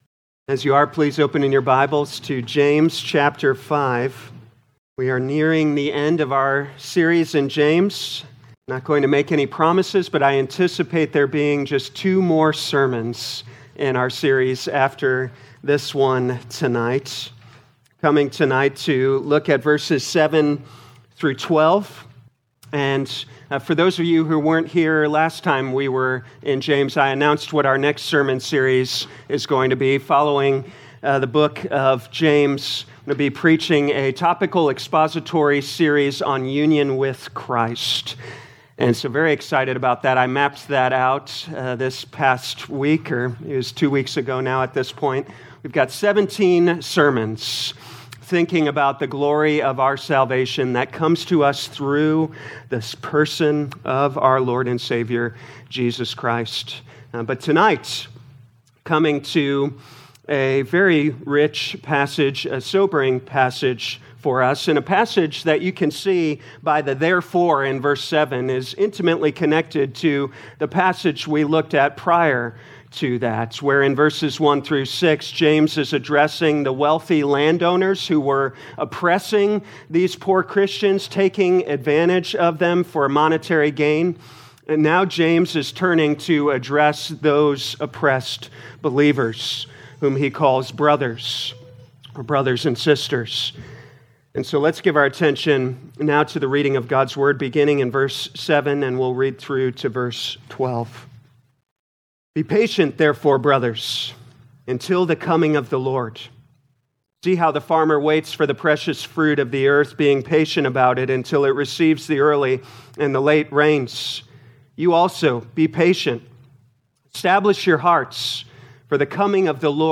2026 James Evening Service Download